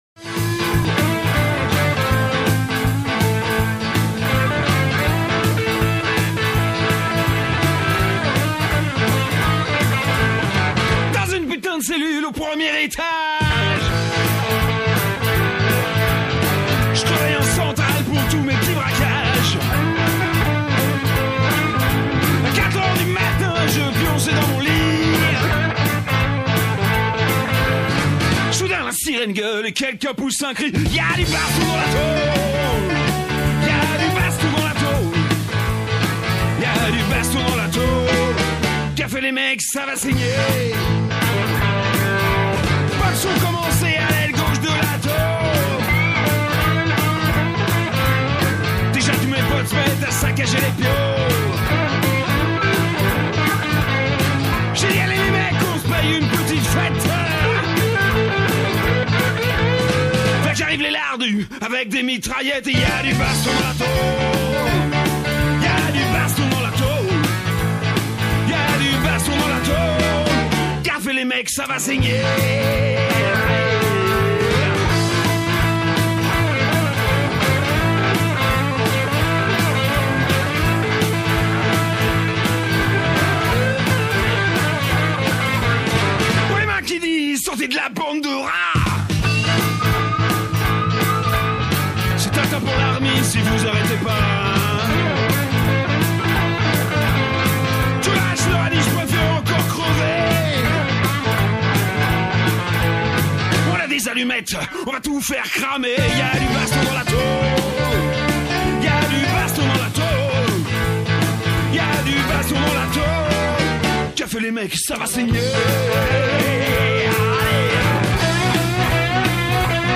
Elle donne la parole aux prisonniers, prisonnières et leurs proches & entretient un dialogue entre l’intérieur et l’extérieur des prisons
Émission de l’Envolée du vendredi 6 septembre 2024 L’Envolée est une émission radio pour en finir avec toutes les prisons.